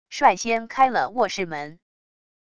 率先开了卧室门wav音频生成系统WAV Audio Player